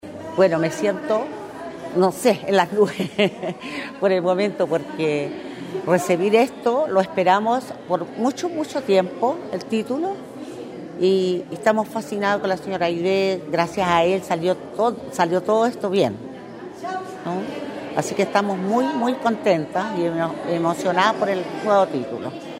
En el salón principal de la casa de la cultura de la comuna de Illapel se llevó a cabo la entrega de 67 títulos de domino a beneficiarios y beneficiarias de la capital provincial, instancia que fue encabezada por el Subsecretario de Bienes Nacionales Sebastián Vergara, quien junto a la Delegada Presidencial provincial de Choapa, Nataly Carvajal, el Seremi de Bienes Nacionales, Marcelo Salazar y el edil comunal, Denis Cortes Aguilera, apreciaron como se cristalizaba el sueño de estas familias illapelinas de ser propietarias del inmueble que habitan.